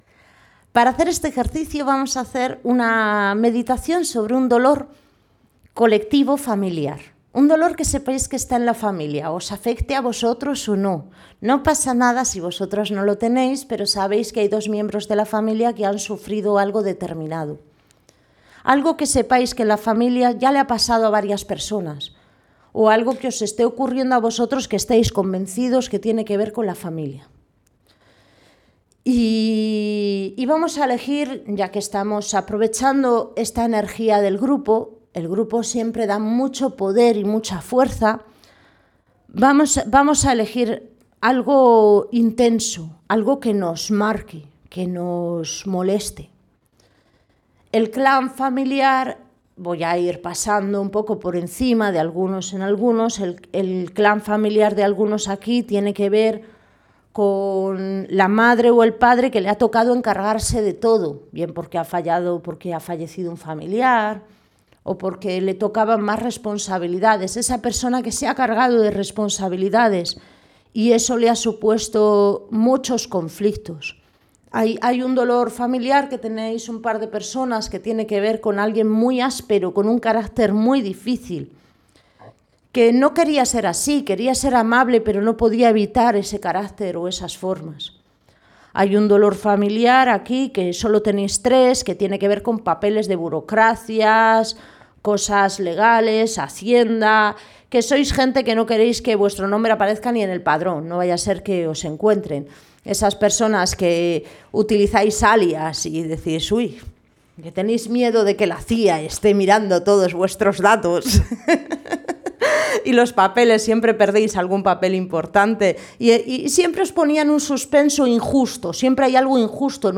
Meditación-charla abrazando el dolor. Enfocado a heridas y conflictos familiares